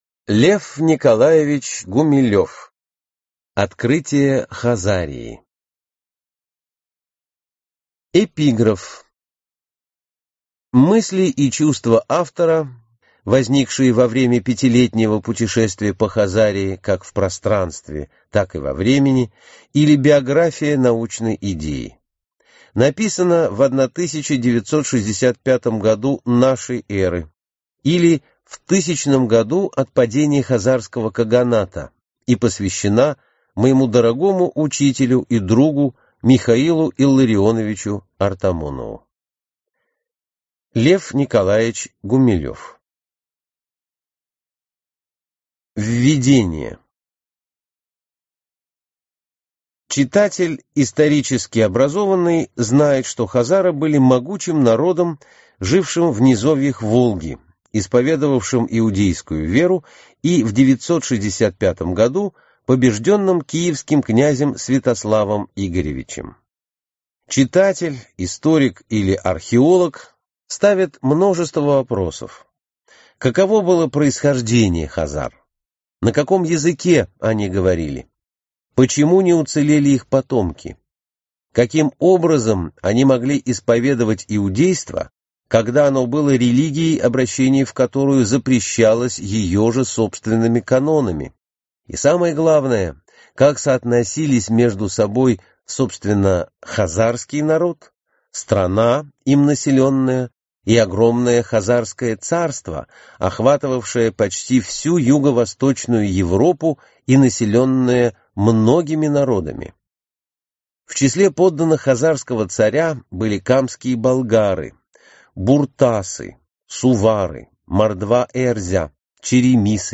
Аудиокнига Открытие Хазарии | Библиотека аудиокниг